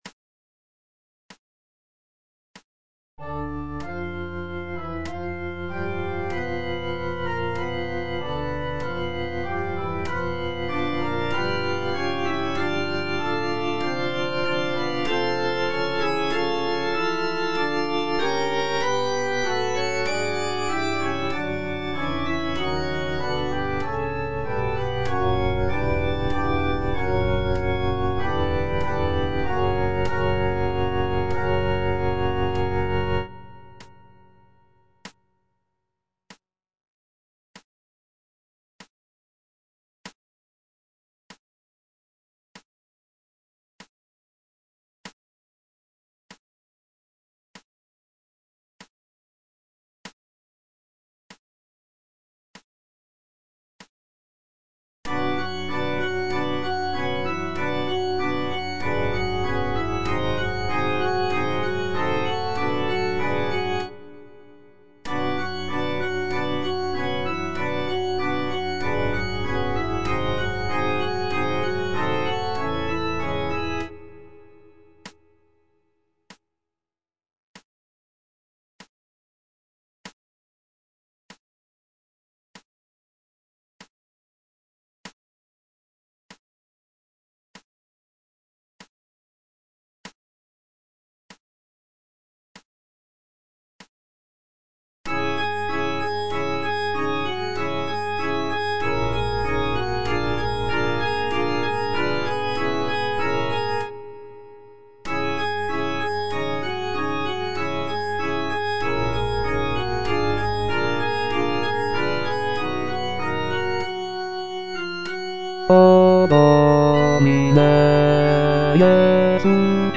G. FAURÉ - REQUIEM OP.48 (VERSION WITH A SMALLER ORCHESTRA) Offertoire - Bass (Voice with metronome) Ads stop:
This version features a reduced orchestra with only a few instrumental sections, giving the work a more chamber-like quality.